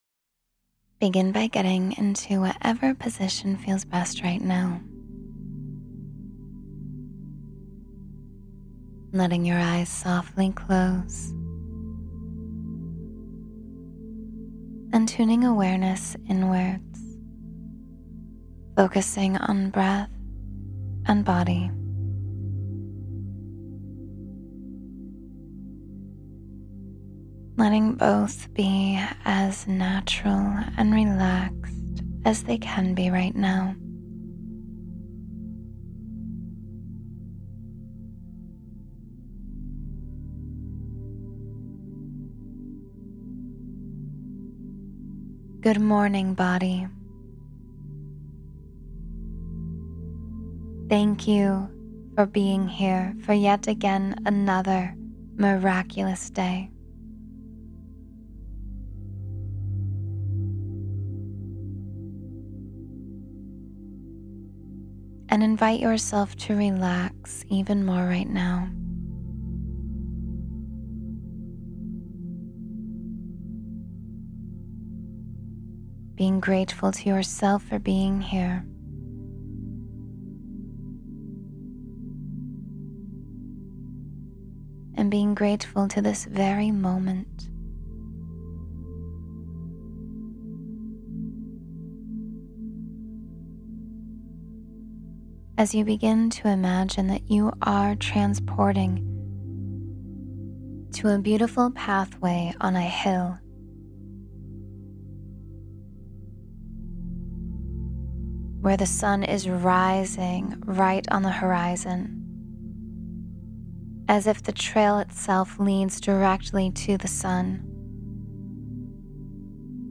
Morning Visualization for Joy and Wonder